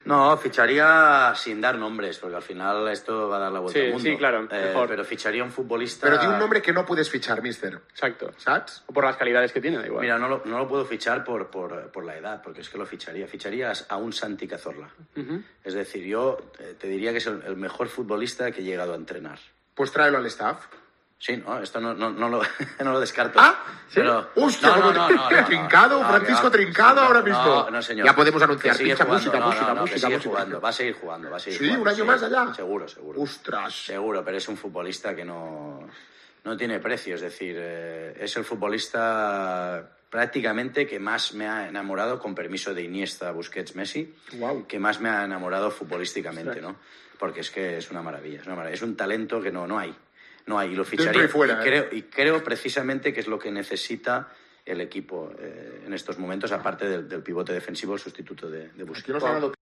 Xavi Hernández, entrenador del Fútbol Club Barcelona, concedió una entrevista a Jijantes donde habló de Santi Cazorla.